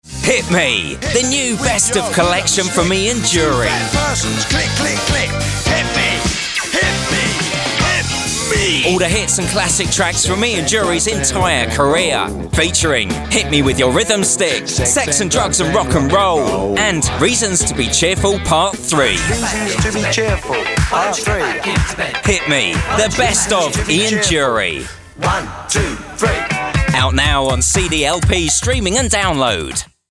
Anglais (britannique)
Imagerie radio
Neumann TLM-103
Cabine insonorisée
Âge moyen
Baryton